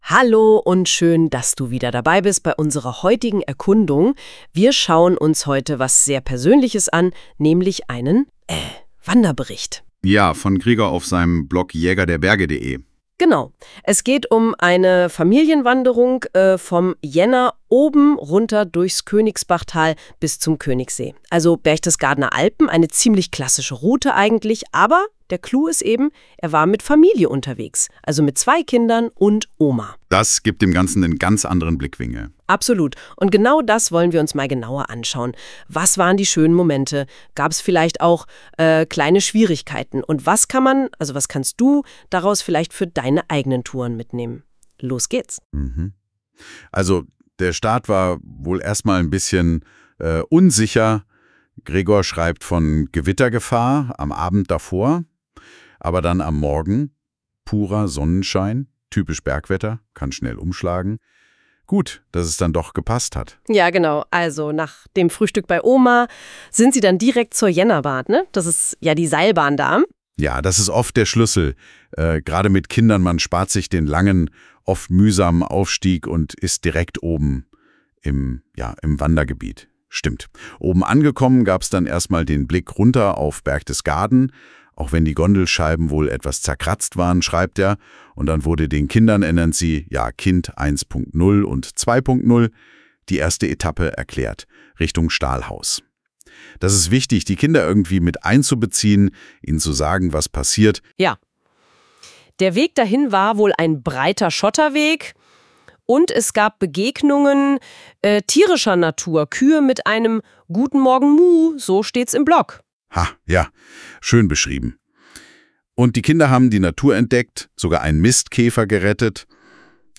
*AI Zusammenfassung